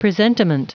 Prononciation du mot presentiment en anglais (fichier audio)
Prononciation du mot : presentiment